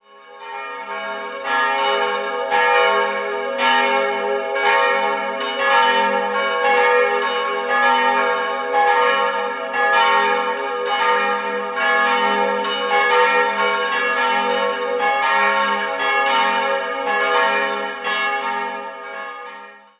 Der freundliche Innenraum mit drei Altären und der großen gewölbten Holzdecke hat eine angenehme Atmosphäre. 3-stimmiges Gloria-Geläute: as'-b'-des'' Die Glocken 1 und 3 wurden 1958 von Friedrich Wilhelm Schilling gegossen, die mittlere stammt vermutlich von Kleeblatt (Amberg) aus der Barockzeit.